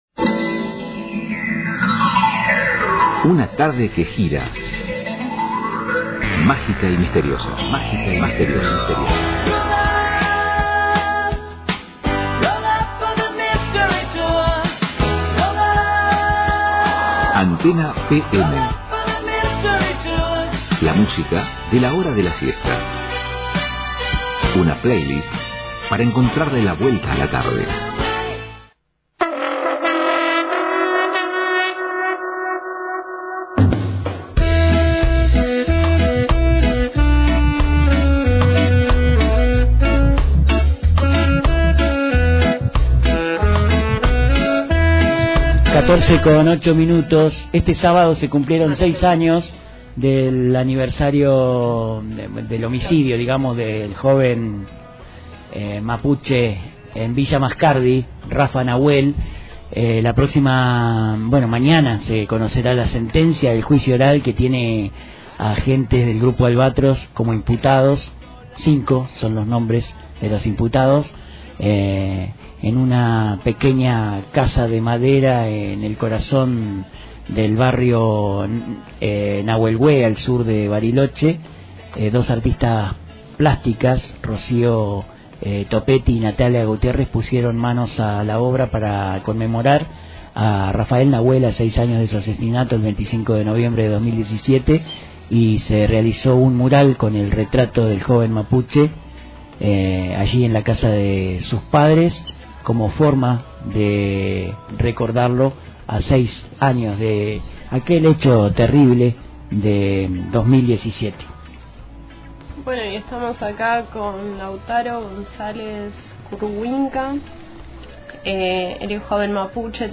En su paso por el Estudio «Madres de Plaza de Mayo», nos compartió una milonga cuya letra pertenece a un vecino de su localidad, que refleja la visión del territorio y su defensa.